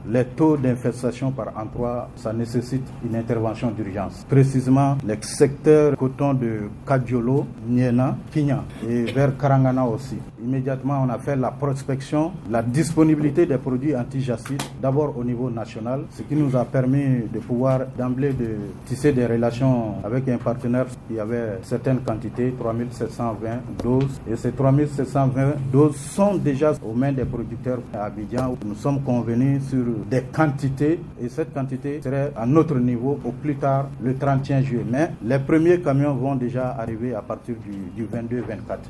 Le ministère de l’Agriculture a fait le point ce lundi sur la campagne 2025-2026 lors d’une conférence de presse. Paiement des recettes des cotonculteurs pour la saison écoulée, gestion des engrais subventionnés, lutte contre les jassides, entre autres. Les autorités se montrent confiantes quant à l’évolution des cultures.